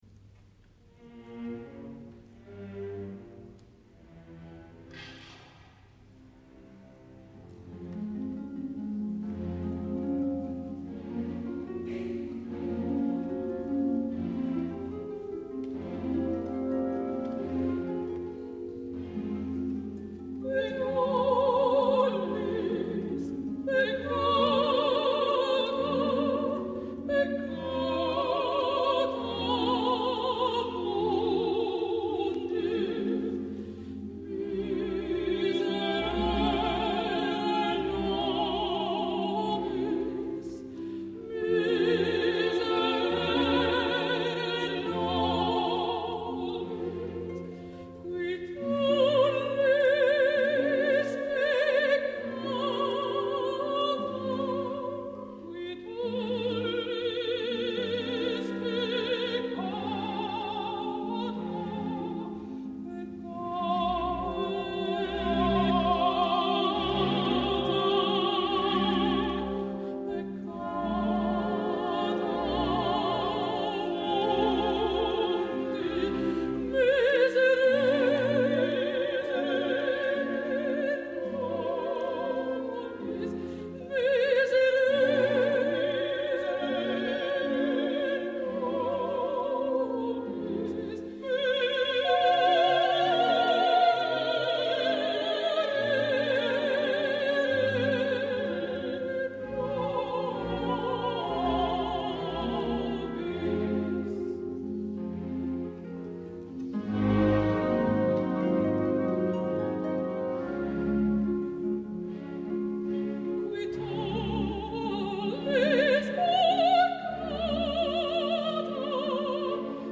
Gioachino Rossini va escriure la Petite Messe Solennelle l’any 1863, originalment escrita per a 12 cantants (4 solistes i 8 de cor), dos pianos i harmònium i la va dedicar a la comtessa Louise Pillet-Will.